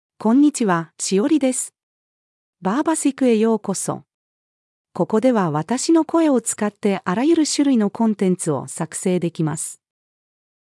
ShioriFemale Japanese AI voice
Shiori is a female AI voice for Japanese (Japan).
Voice sample
Listen to Shiori's female Japanese voice.
Shiori delivers clear pronunciation with authentic Japan Japanese intonation, making your content sound professionally produced.